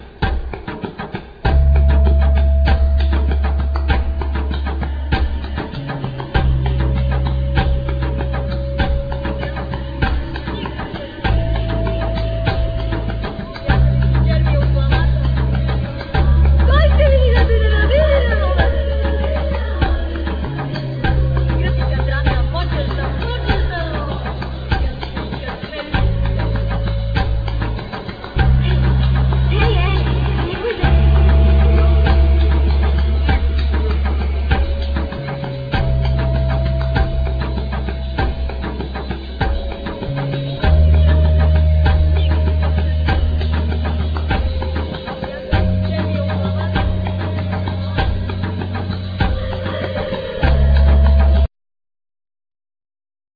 Voice,Samples,Percussion
Bass
Drums
Oceanic sampled guitar
Fire-samples guitar
Mimetic beats
Voices,Rabab,Clarinet,Pandero
Flute